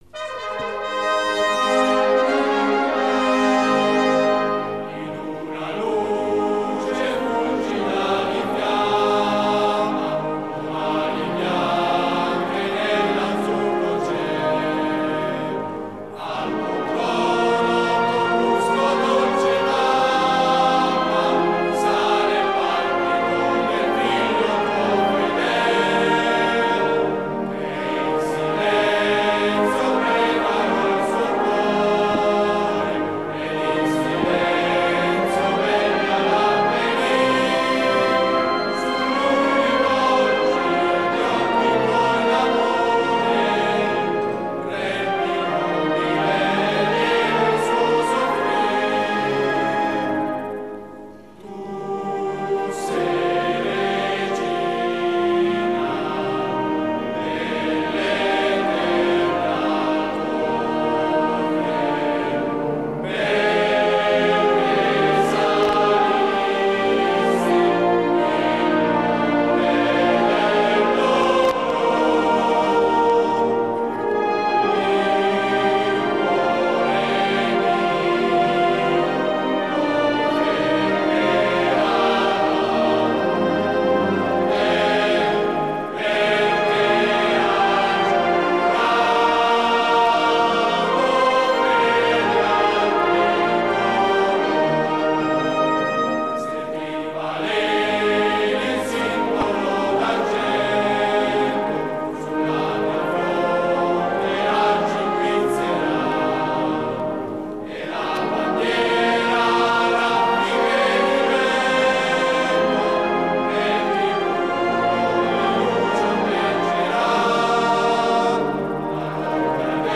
inno-virgo-fidelis.mp3